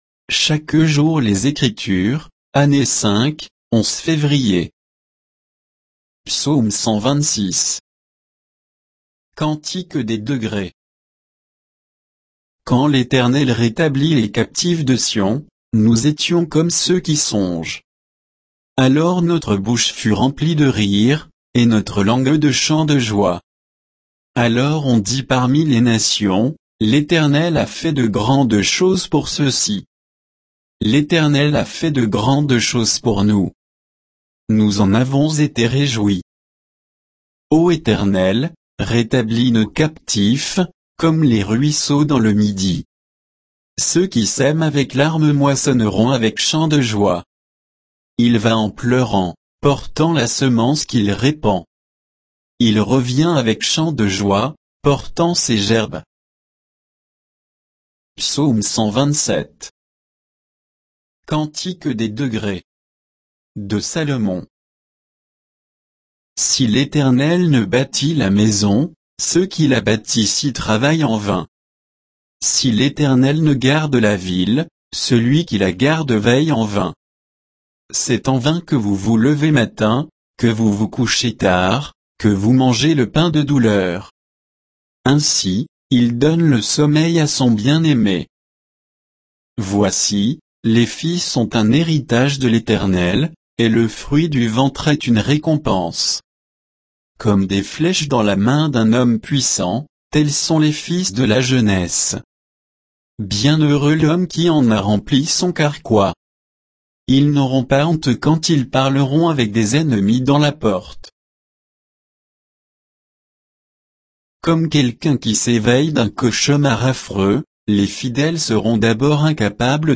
Méditation quoditienne de Chaque jour les Écritures sur Psaumes 126 et 127